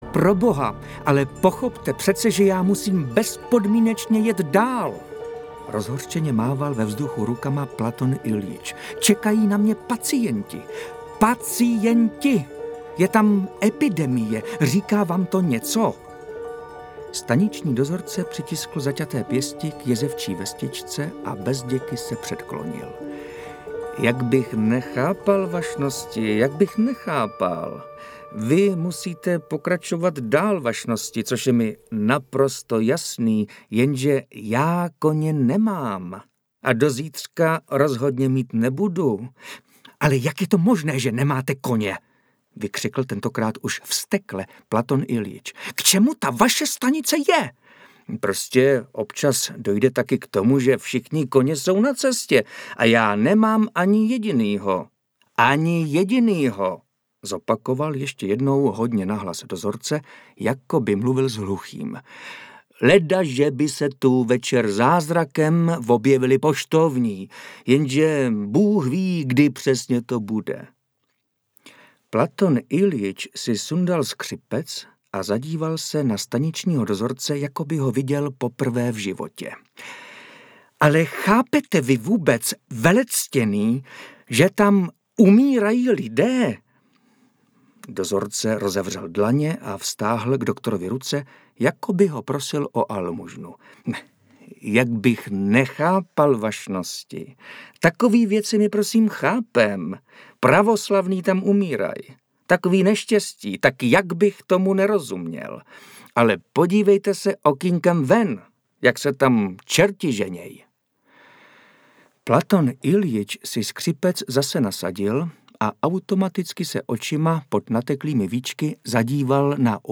Audiobook
Read: Martin Myšička